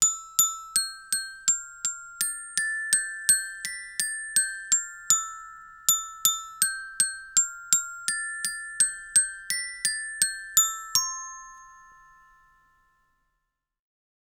Melodía sencilla interpretada con un carillón
idiófono
percusión
campanilla
carillón